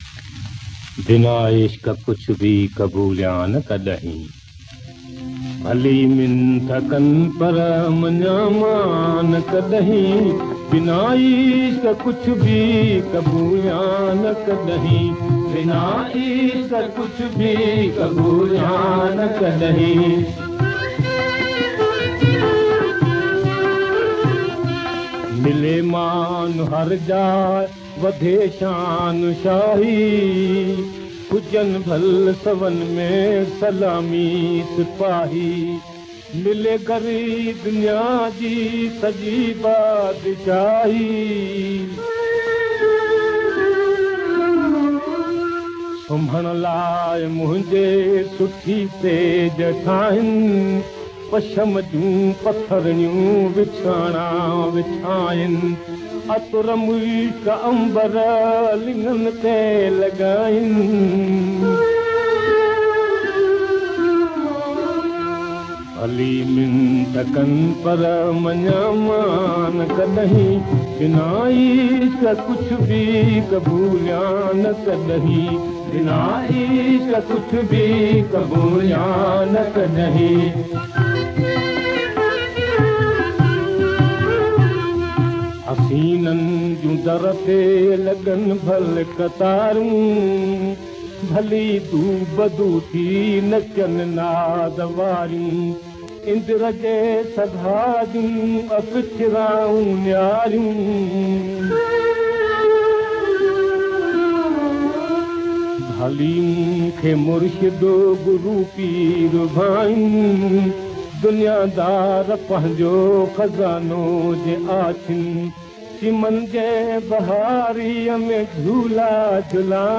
Converted from very old Gramophone records.